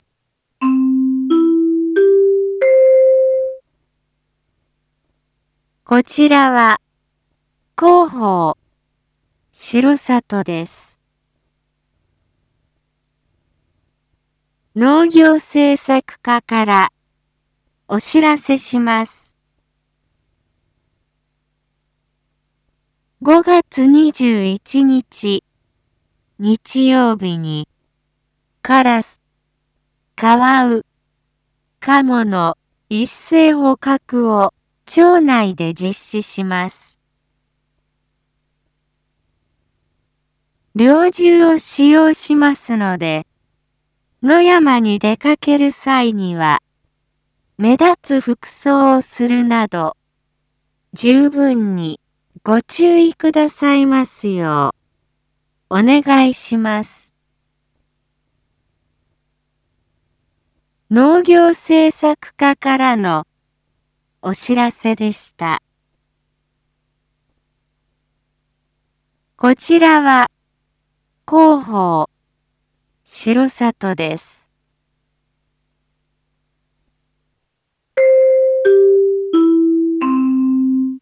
一般放送情報
Back Home 一般放送情報 音声放送 再生 一般放送情報 登録日時：2023-05-19 19:01:22 タイトル：R5.5.21 19時放送分 インフォメーション：こちらは、広報しろさとです。